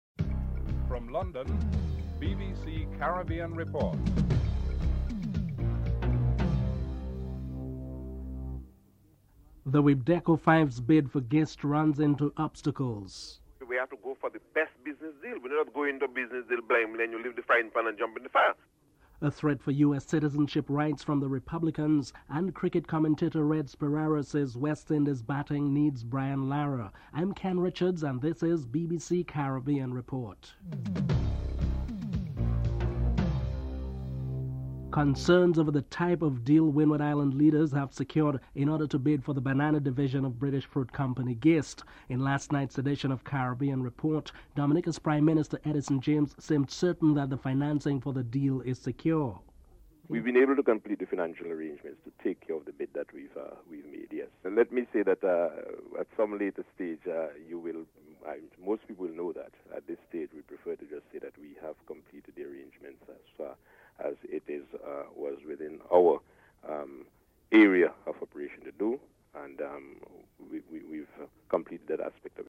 9. Recap of top stories (14:39-15:15)